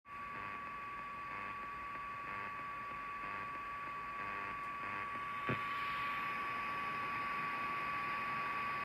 mein VONG macht störgeräusche
Einzeln angeschlossen stört es auch. Mit verschiedenen netzteilen direkt und mit ner powerbank probiert - immer dasselbe.